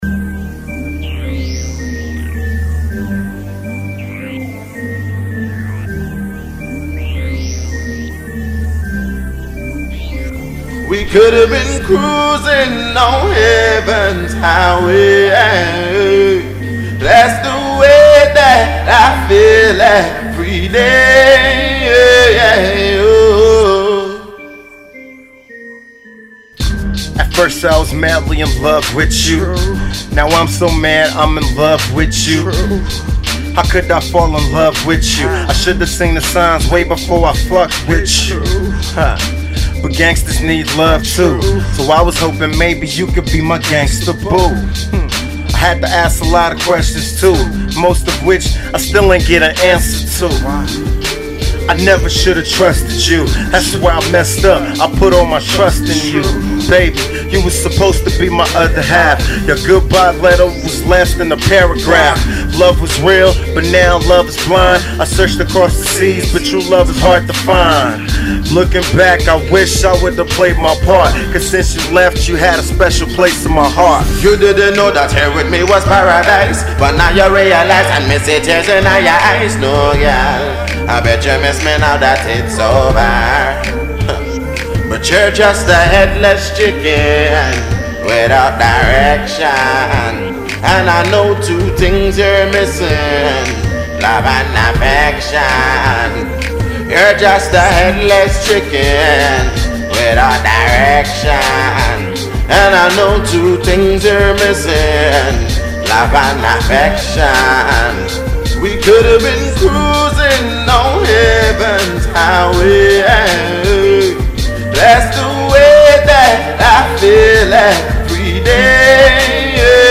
vocally flosses on the hook